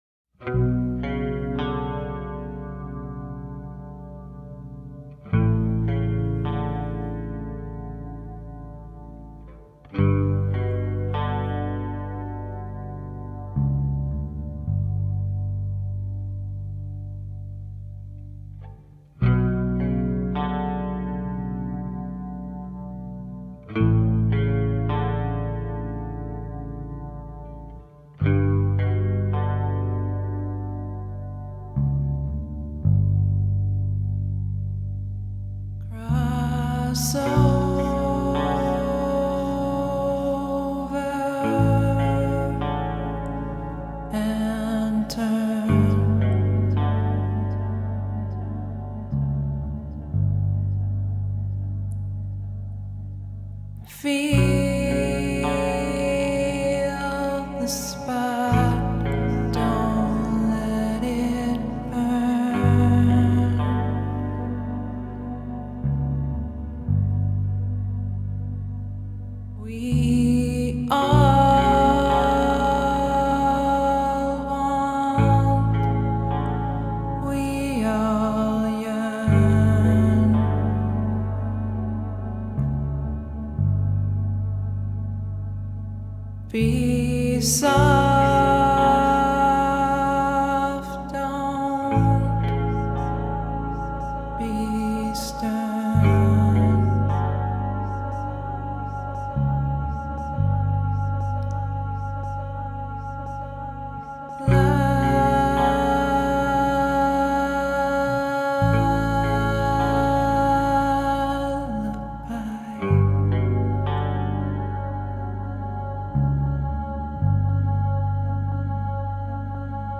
سبک موسیقی آلترناتیو راک سبک موسیقی ایندی راک
سولو آخر آهنگ فوق العادس